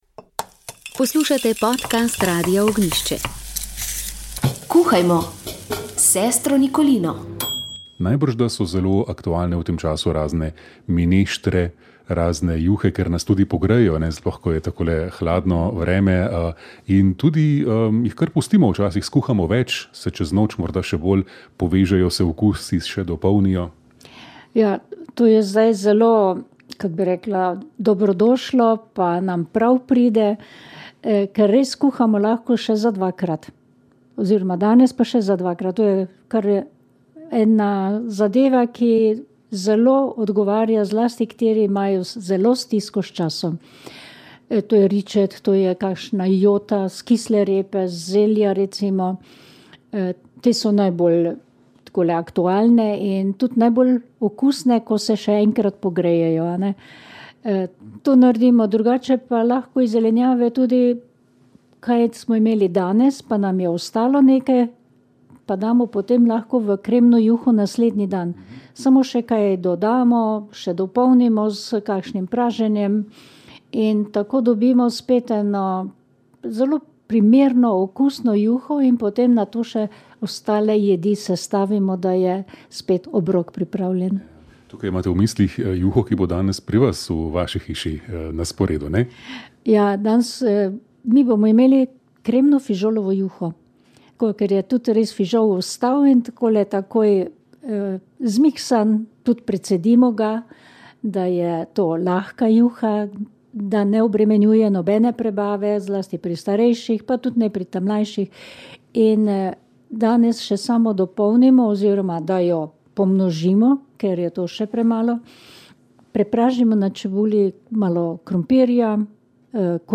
Gost nadškof Marjan Turnšek je v radijski katehezi razmišljal o duhovnem svetu.